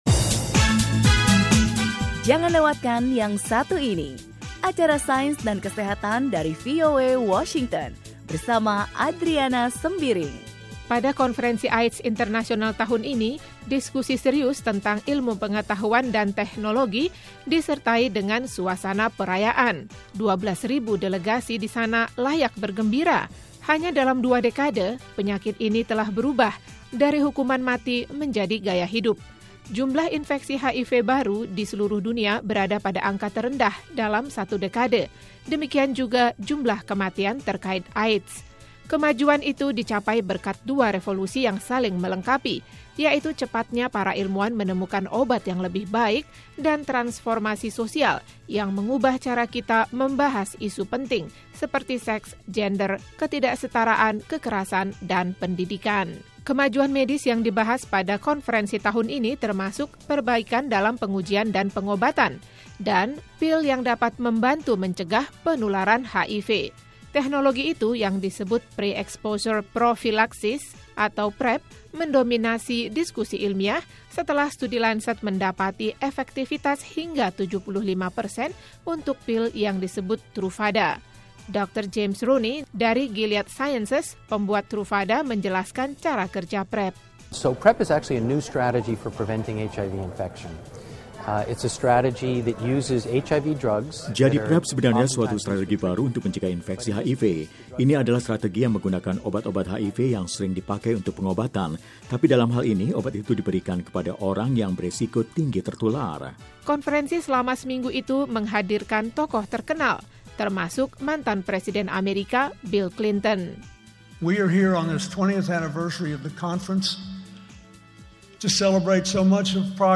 Dalam 20 tahun, Konferensi AIDS Internasional telah membengkak dari peserta sekitar 2.000 ilmuwan pada pertemuan perdana tahun 1985 di Atlanta, menjadi pertemuan 12.000 ilmuwan dari berbagai bidang, aktivis dan orang yang hidup dengan virus itu. Konferensi tahun ini di Melbourne, Australia, mencakup berbagai topik, mulai dari ilmu pengetahuan murni sampai isu sosial. Laporan wartawan VOA